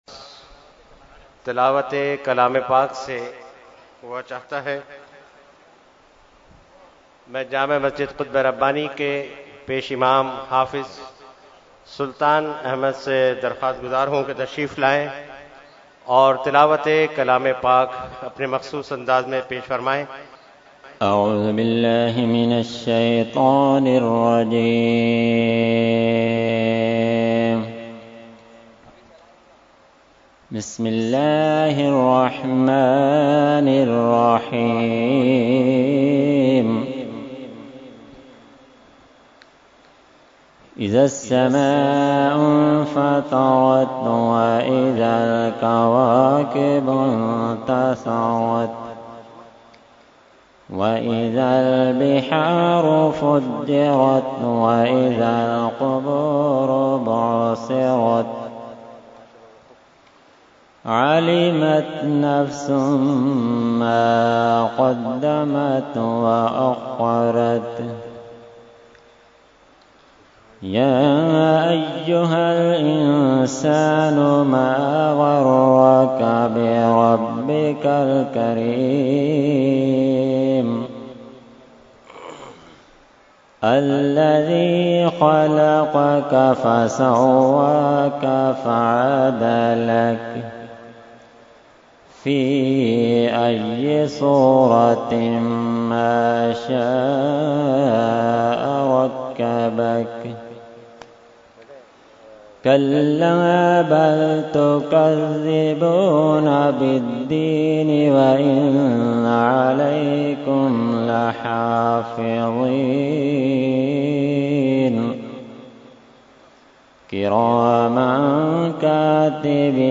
Category : Qirat | Language : ArabicEvent : Urs Ashraful Mashaikh 2017